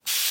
Звук пшика духов